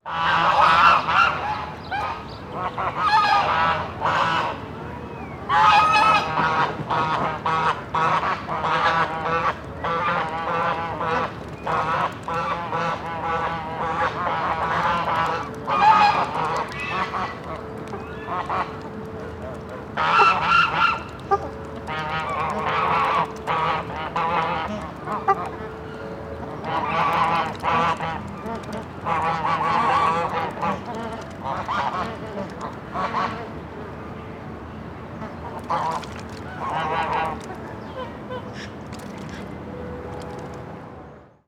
Zoo de Madrid: aves acuáticas 1: ánade, cigüeña, flamenco, ganso, grulla, pelícano, tarro